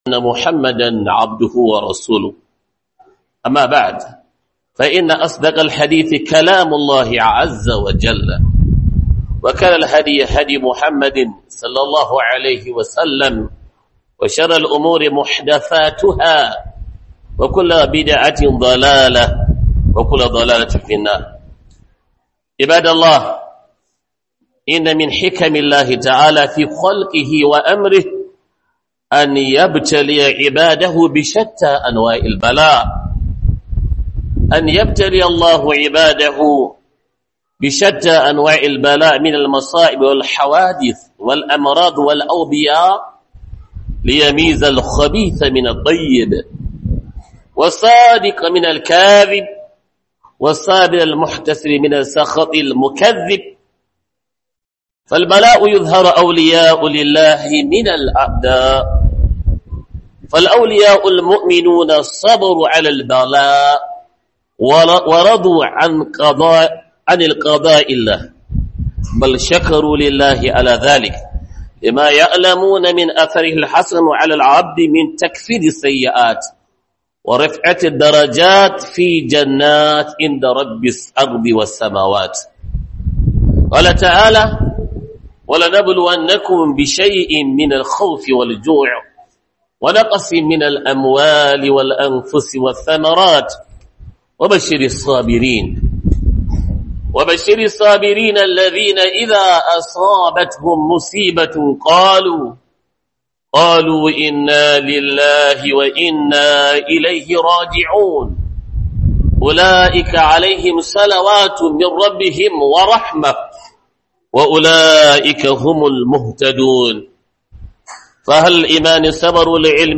Huduba - Mu Koma Ga Allah